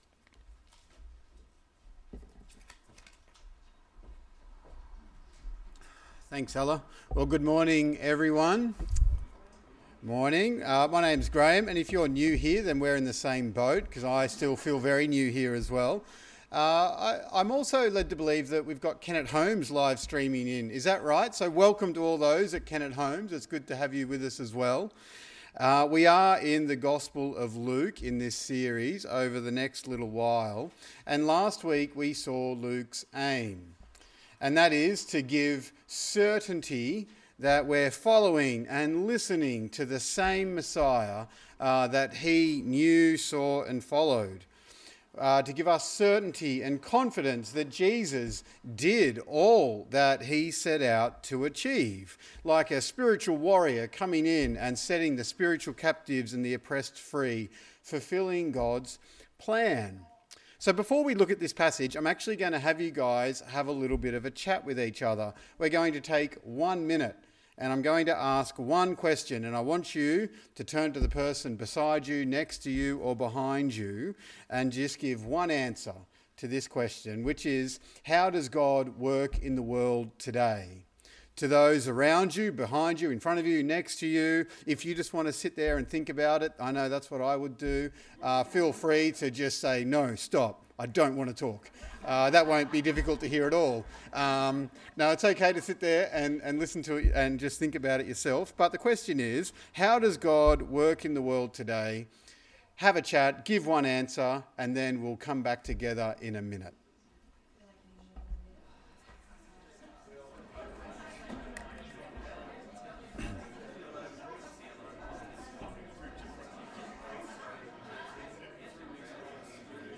This sermon was preached on 12/10/25 at all services of Helensburgh and Stanwell Park Anglican Church.